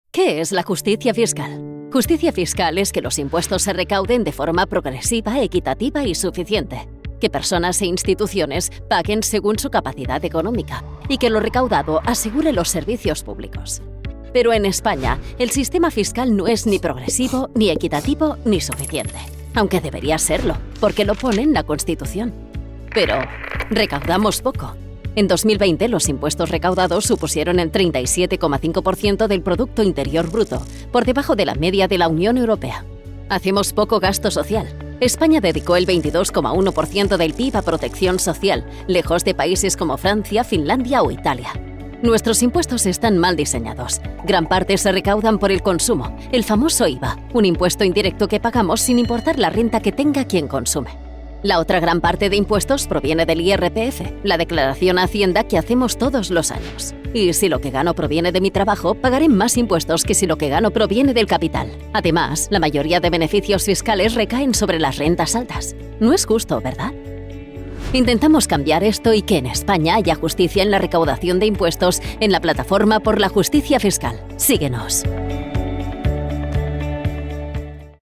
Neumann U87 + Studiobricks.
kastilisch
Sprechprobe: Industrie (Muttersprache):